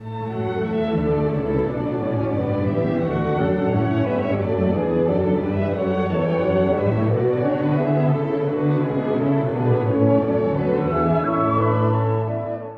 ↑古い録音のため聴きづらいかもしれません！（以下同様）
美しく、息の長い旋律で始まります。
西洋のロマン派を感じさせる楽章です。
しかし曲が進むと、踊るような強めの拍感が出てきます。
これら踊るような拍感と西洋音楽との対比や、ミックスがとても面白い楽章です。